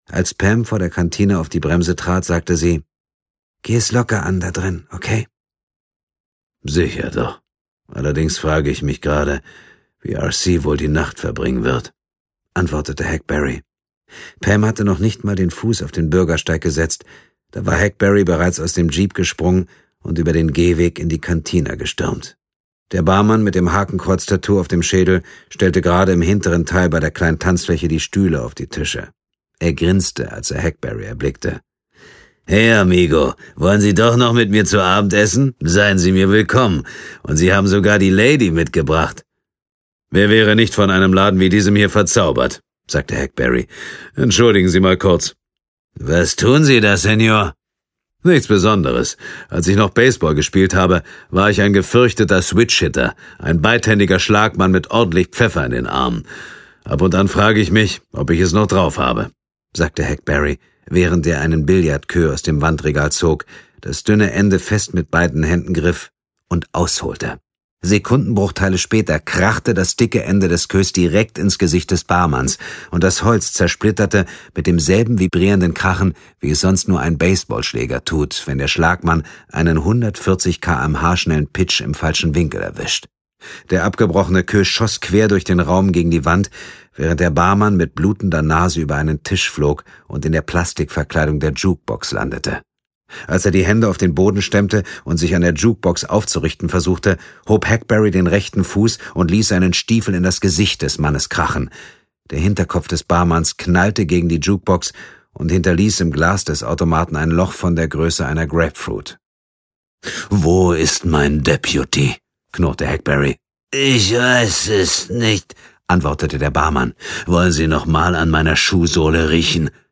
Dietmar Wunder (Sprecher)
2015 | Gekürzte Lesung
Außerdem ist er ein gefragter und exzellenter Hörbuchsprecher, der seine markante Stimme Größen des Thriller-Genres wie Grangé, Larsson und Deaver leiht.